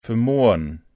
Begriffe von Hochdeutsch auf Platt und umgekehrt übersetzen, plattdeutsche Tonbeispiele, Schreibregeln und Suchfunktionen zu regelmäßigen und unregelmäßigen Verben.